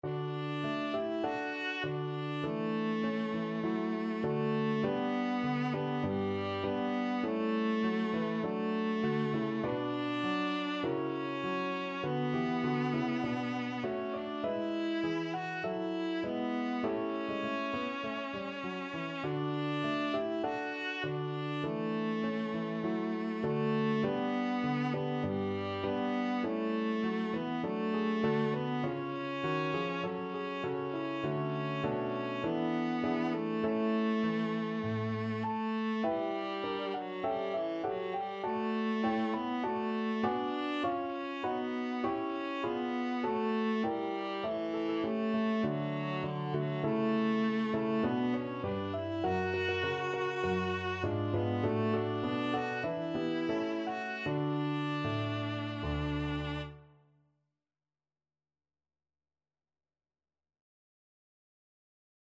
Viola
4/4 (View more 4/4 Music)
D major (Sounding Pitch) (View more D major Music for Viola )
Traditional (View more Traditional Viola Music)
irish_nat_anth_VLA.mp3